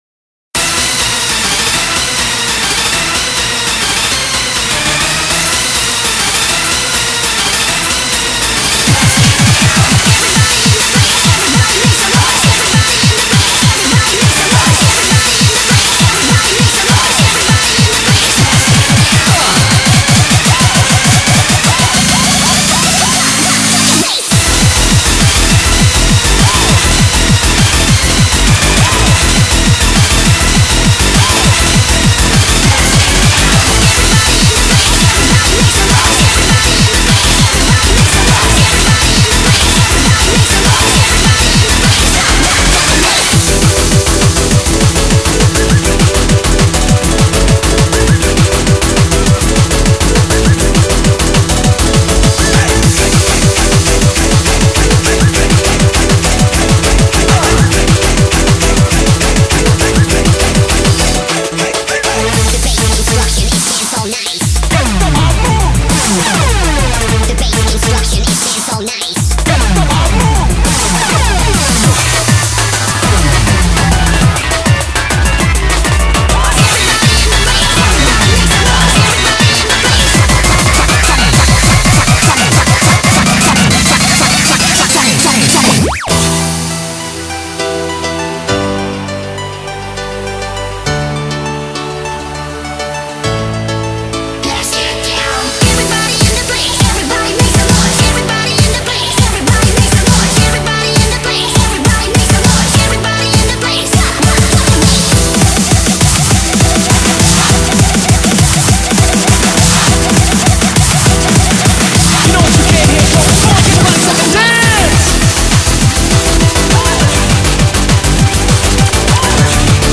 BPM202
Audio QualityPerfect (High Quality)
Audio: Music cut